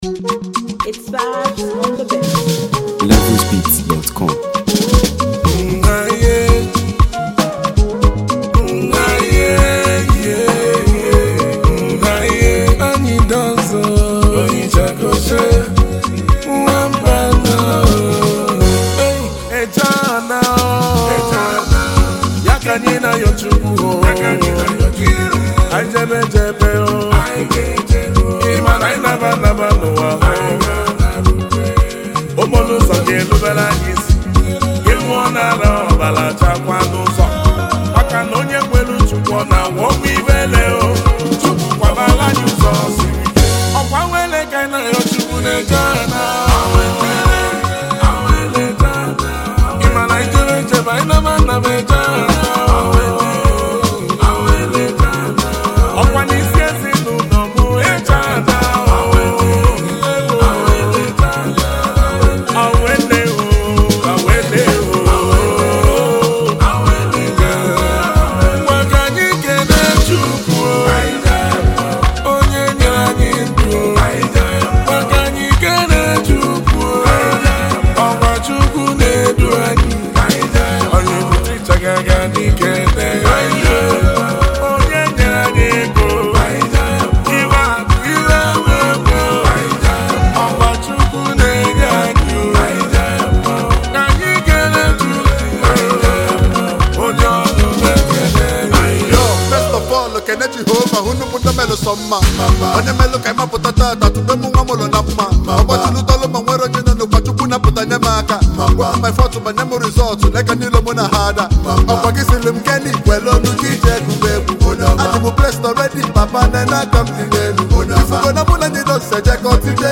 rich and melodious record